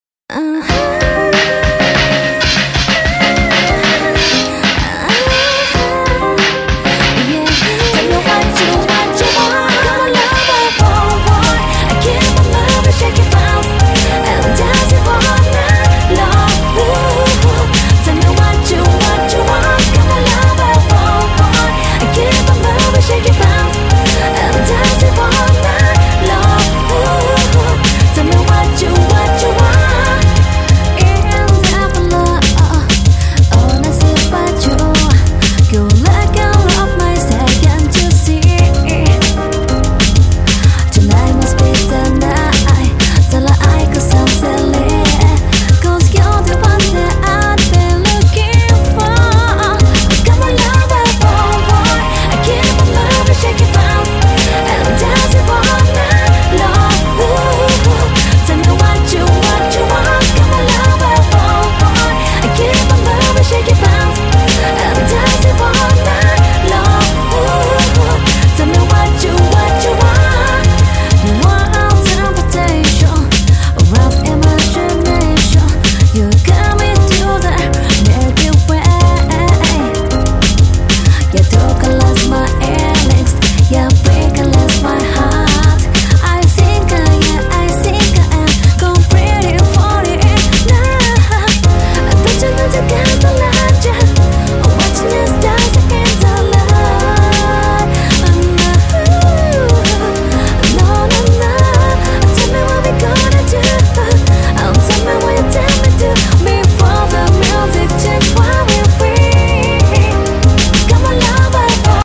11' Super Nice Japanese R&B !!